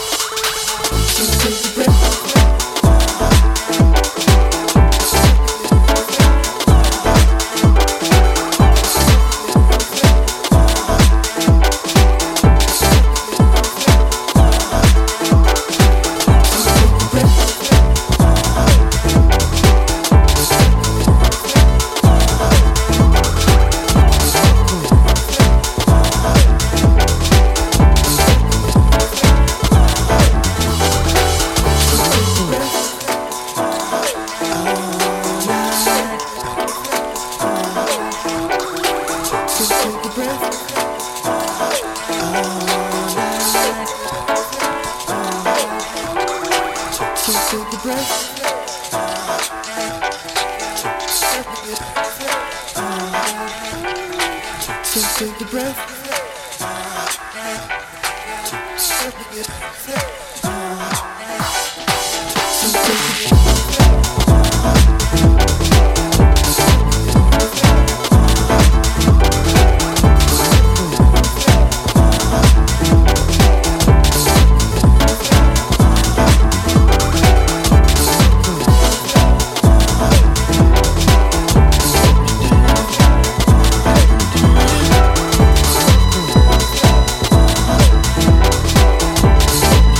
ジャンル(スタイル) DEEP HOUSE / DISCO HOUSE